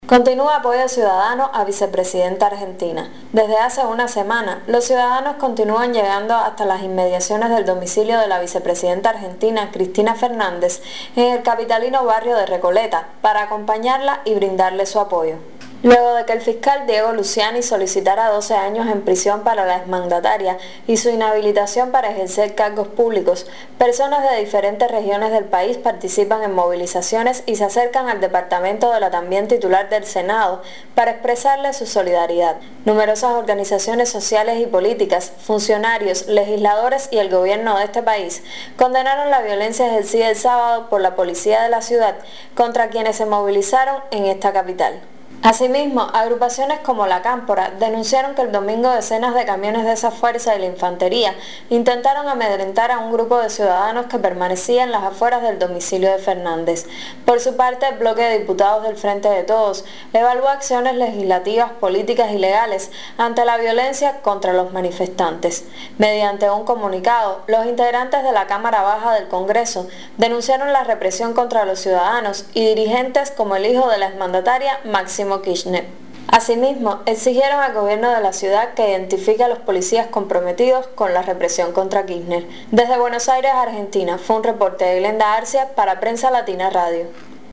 desde Buenos Aires